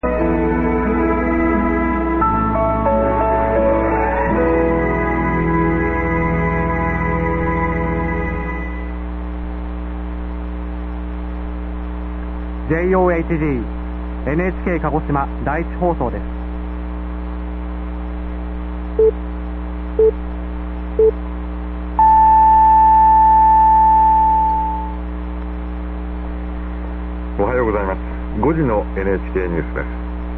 The announcements are pre-recorded and may be read by either a male or a female announcer.
The NHK1 on 576 in Kagoshima (MP3) by a male announcer:
"JOHG NHK Ka-go-she-ma---Die-ee-chee---Hoe-so---des"